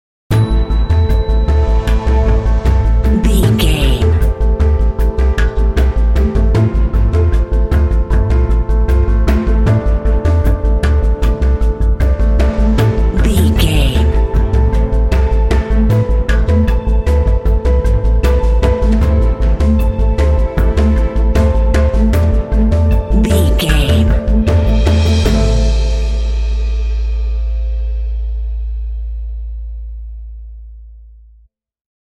Aeolian/Minor
tension
suspense
dramatic
contemplative
drums
piano
strings
synthesiser
cinematic
film score